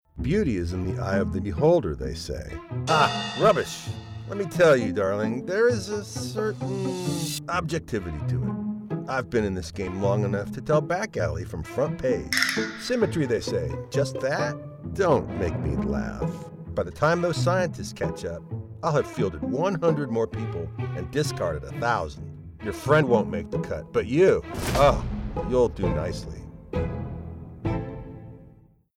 Animation - Cutthroat Competition
USA English, midwest
Middle Aged
I work from a broadcast-quality home studio.